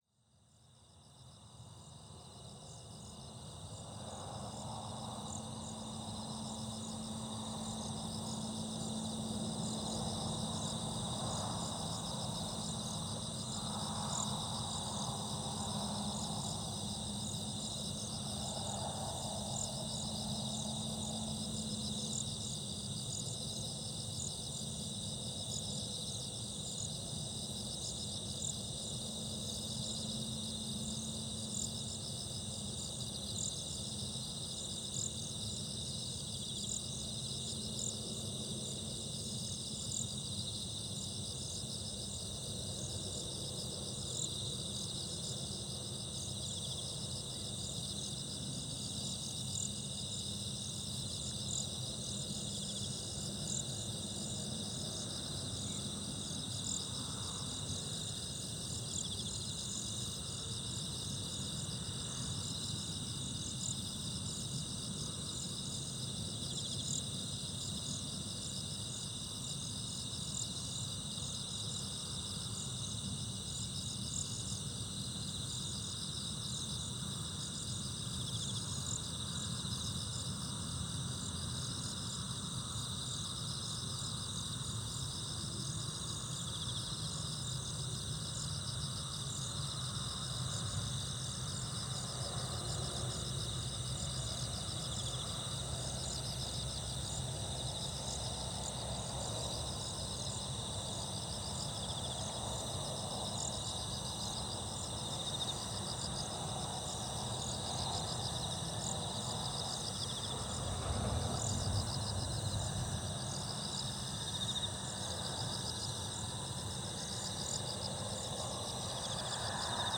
川原のカワラスズ
ZOOM H6, RODE NT5 （OMNI) 2015年9月28日 山形県長井市10年前の録音です。カワラスズの名前の通り、本来の生息地は石のゴロゴロした中流域の川原で、ここは私の知る唯一の生息地でした。